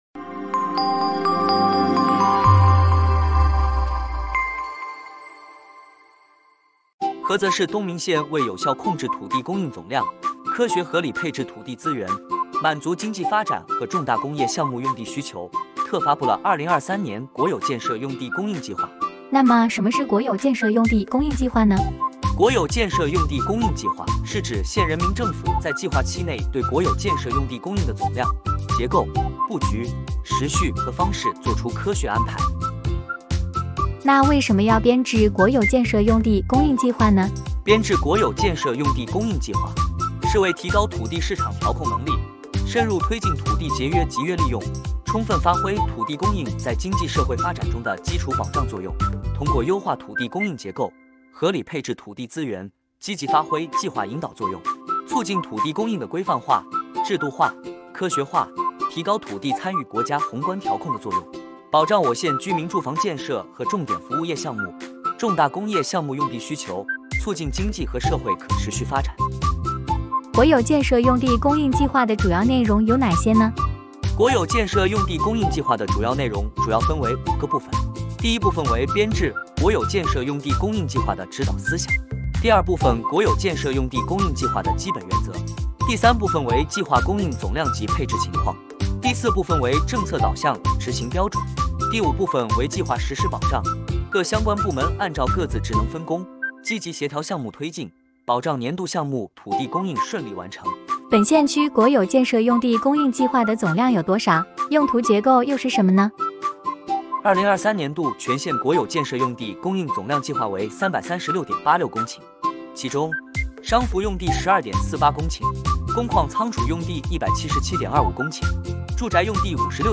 • 分　　类：音频解读